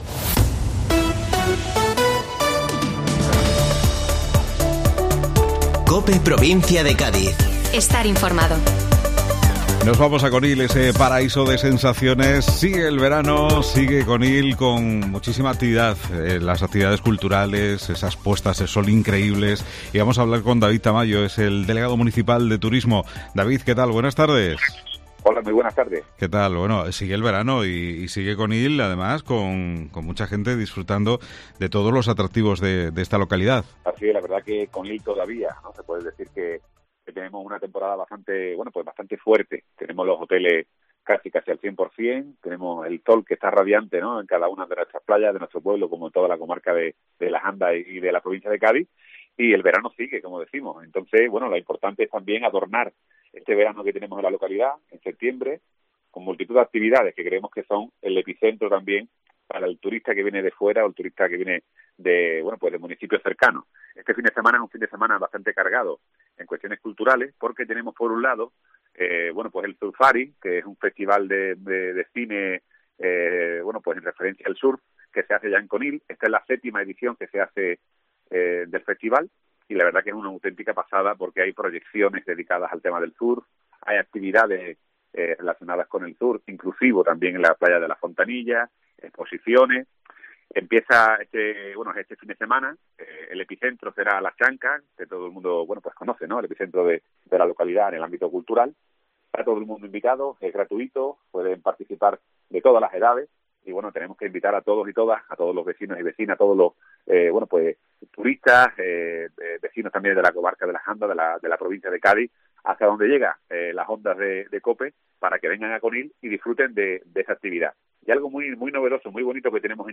David Tamayo, Delegado Municipal de Turismo del Ayuntamiento de Conil habla de la fuerza del sector en el municipio y el refuerzo de actividades culturales para este fin de semana.